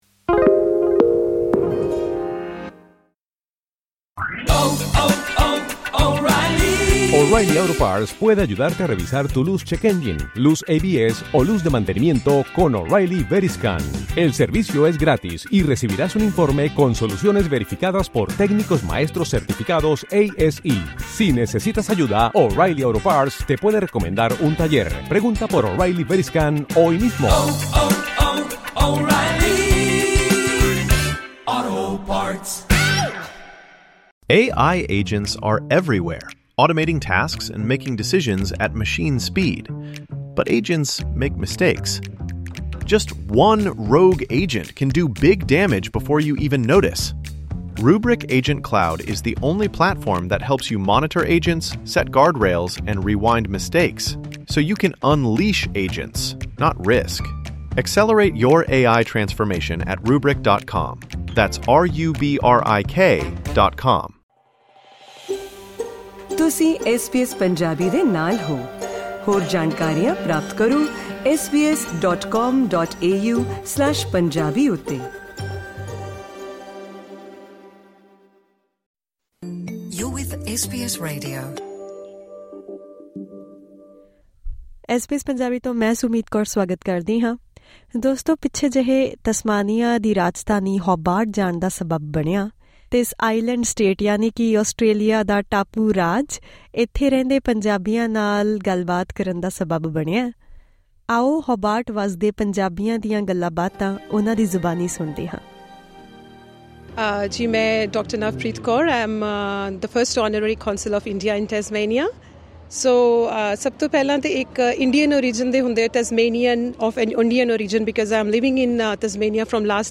The podcast brings voices of the Indian community, their unity and belonging in Tasmania